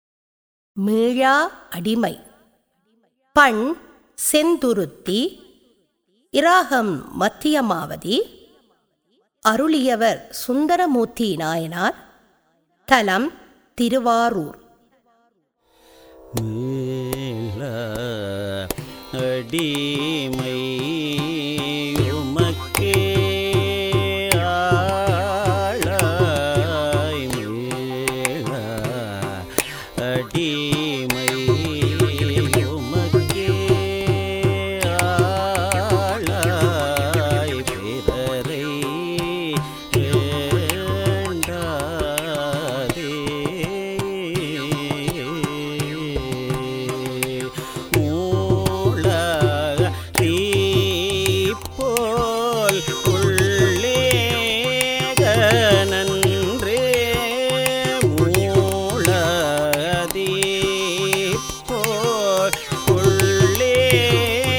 தரம் 10 - சைவநெறி - அனைத்து தேவாரங்களின் தொகுப்பு - இசைவடிவில்